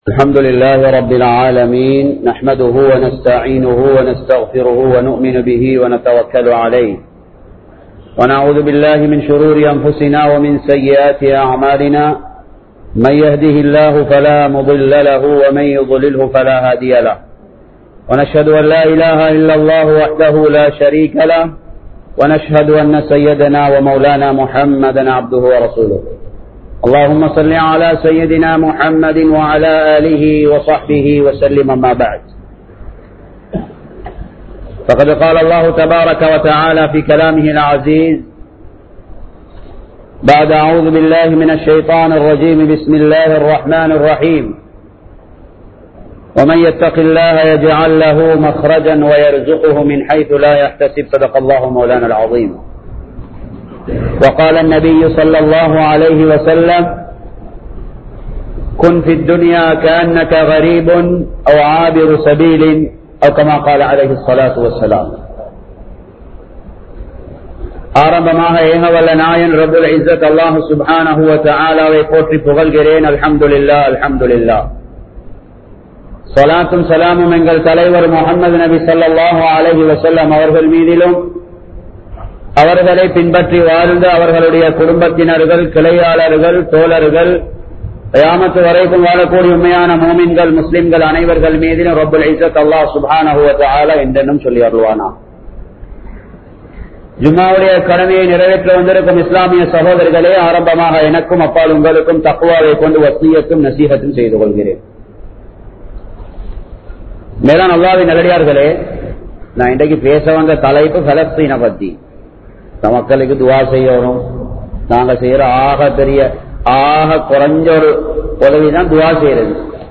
வாழ்வின் சோதனைக்கான காரணங்கள் | Audio Bayans | All Ceylon Muslim Youth Community | Addalaichenai
Umbitchi Jumua Masjidh